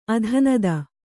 ♪ adhanada